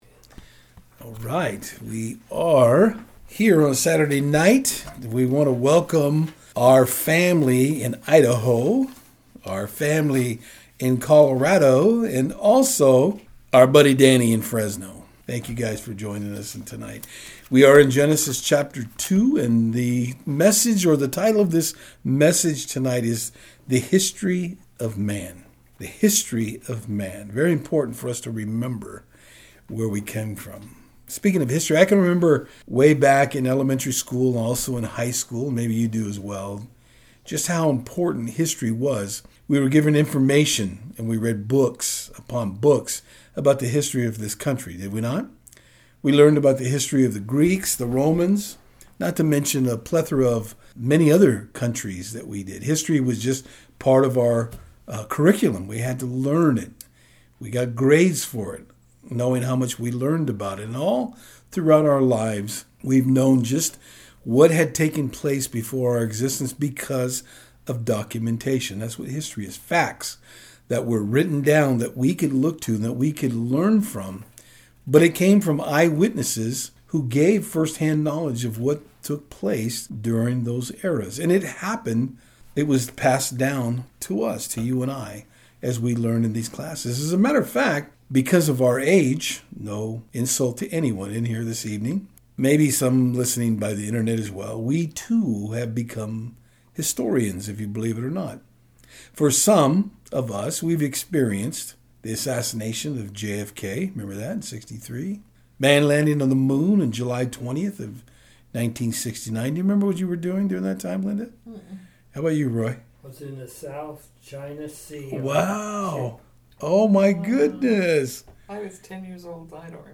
Genesis 2:4-17 Service Type: Saturdays on Fort Hill Today we look at the beginning of man in creation.